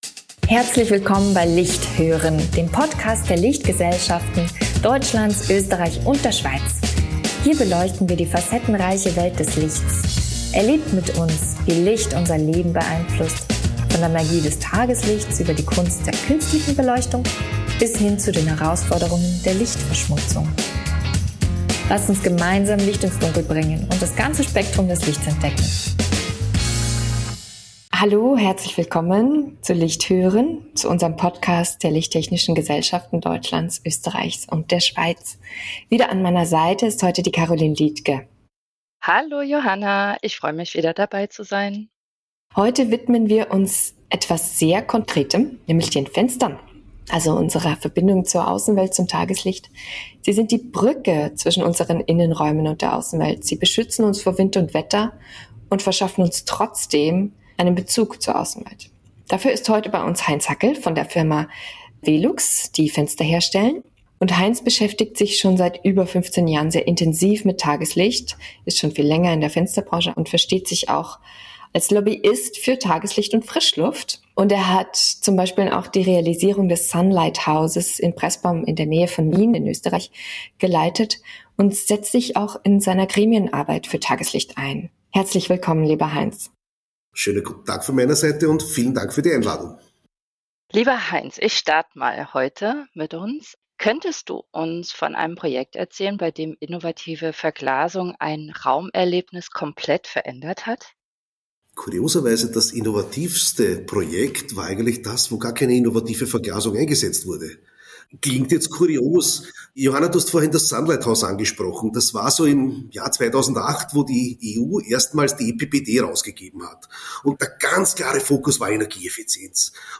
In diesem Interview widmen wir uns den Fenstern.